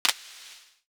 Sizzle Click 7.wav